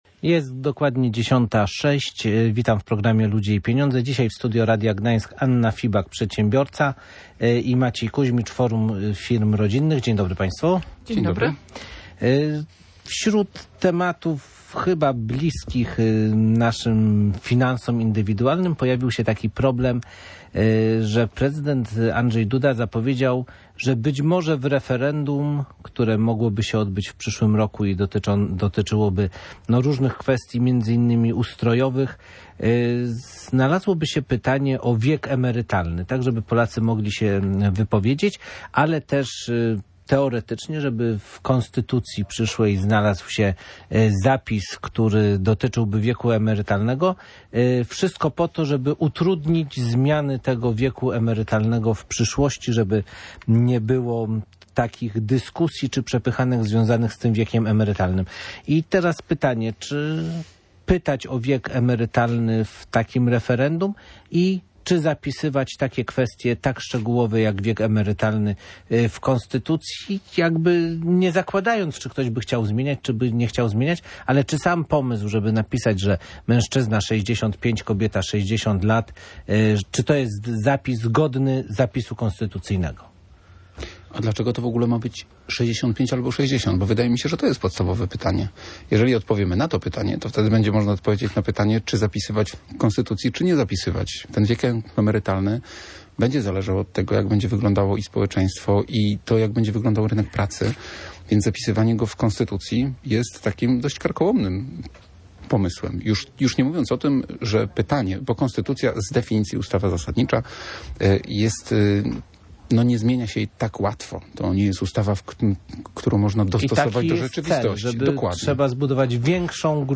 O ocenę poprosiliśmy gości audycji Ludzie i Pieniądze.